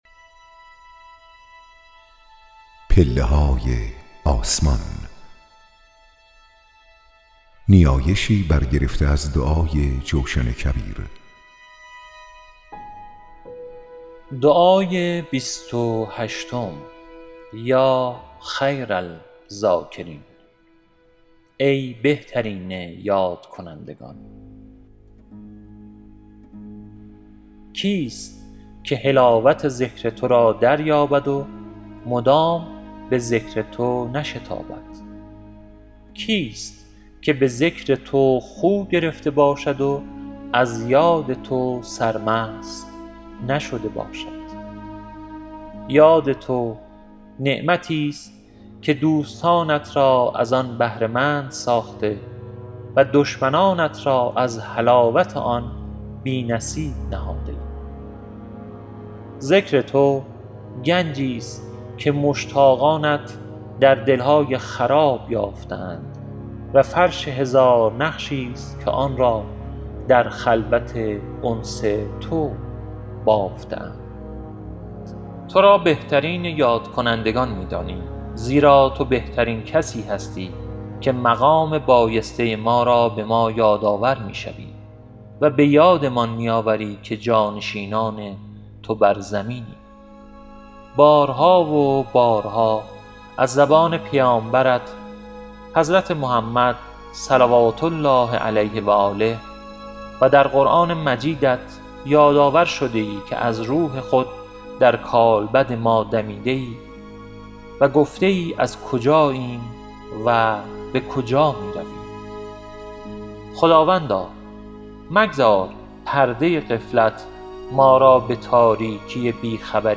لینک خرید کتاب گویای پله‌های آسمان در فیدیبو